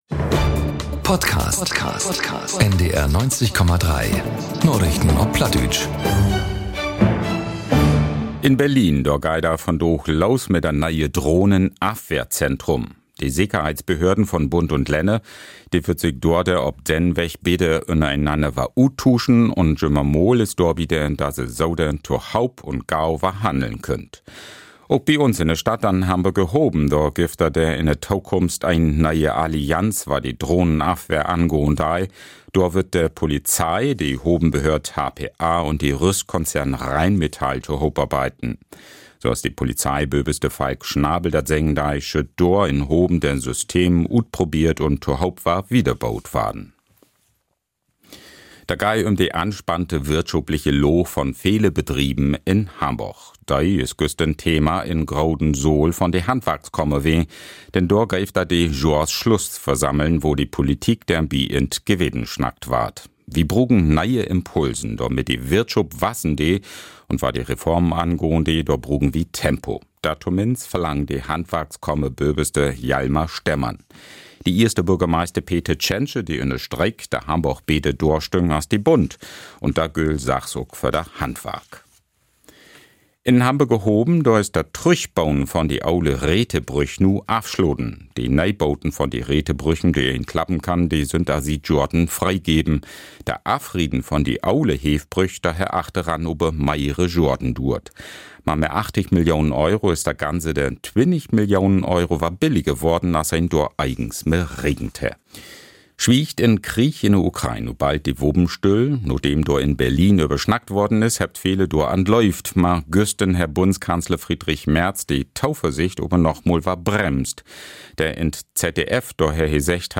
Narichten op Platt 17.12.2025 ~ Narichten op Platt - Plattdeutsche Nachrichten Podcast